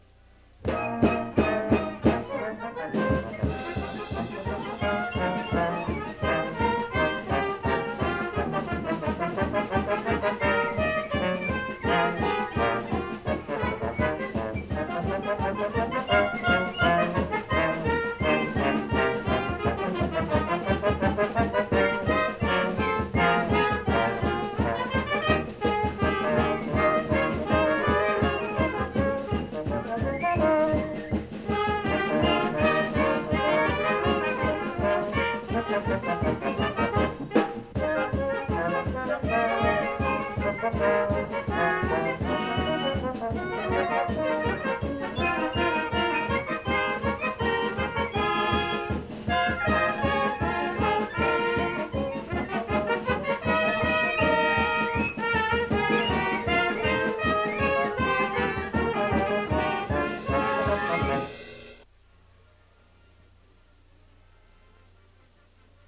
Now you can listen to any or all of the playlist from that first WJU Convention in Key Biscayne, Florida in January,1973.